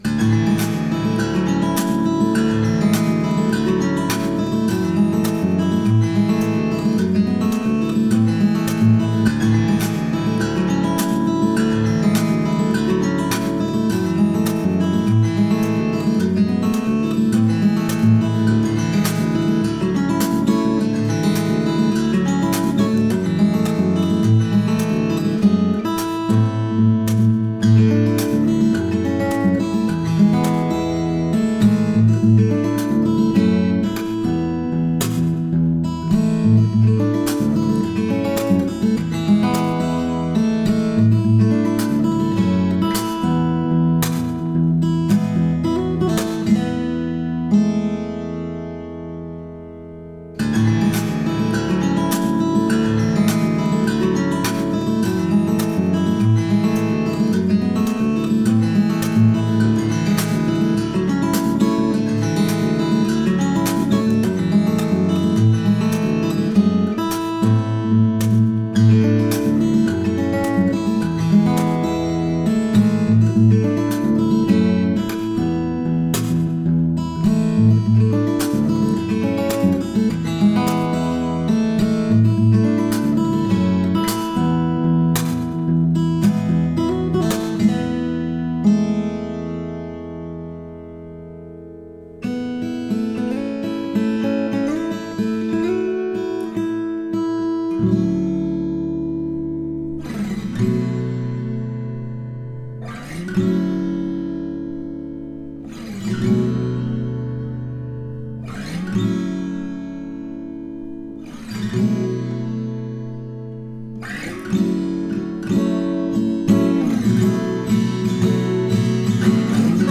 Akoestisch::
Pickup: LR Baggs Anthem
Sprankelend met hele mooie mids.
Klinkt gaaf, zeg!